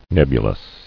[neb·u·lous]